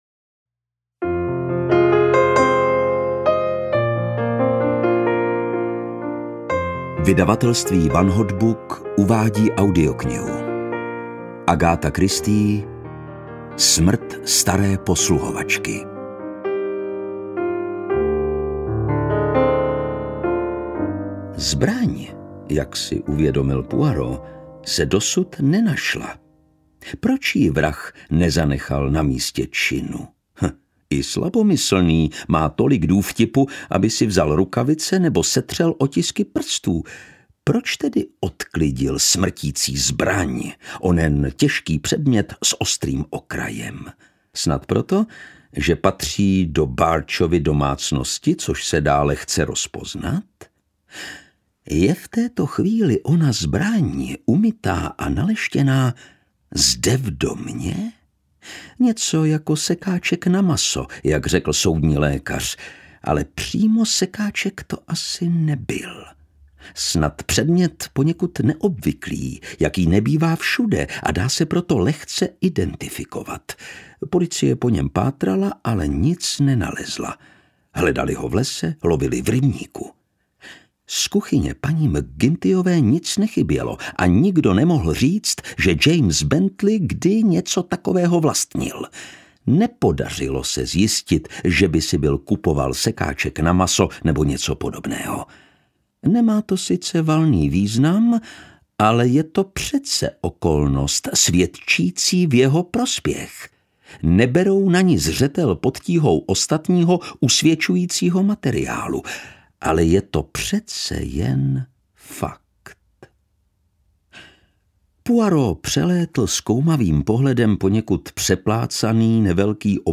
Smrt staré posluhovačky audiokniha
Ukázka z knihy
• InterpretLukáš Hlavica